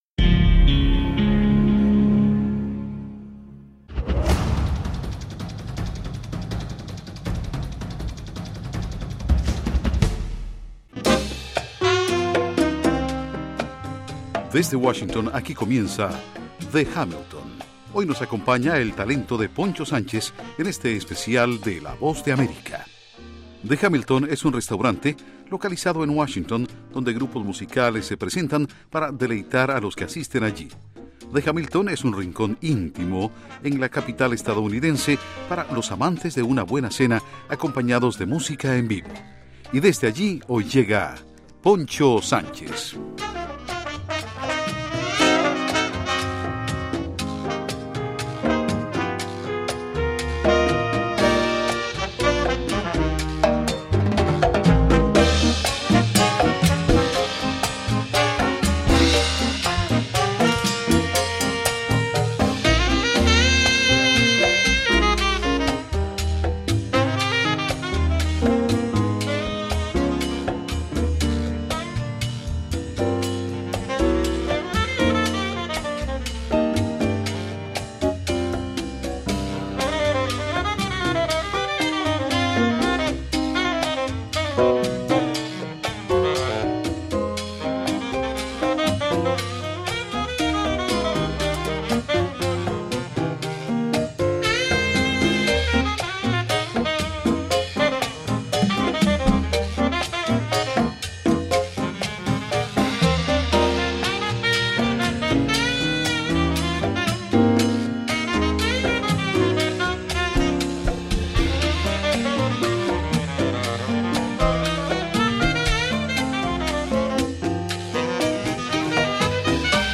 fusión de música latina con jazz
los tambores.